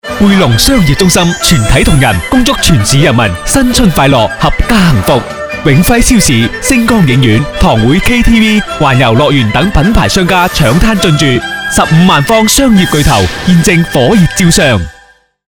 男粤17_广告_地产_汇珑地产贺年.mp3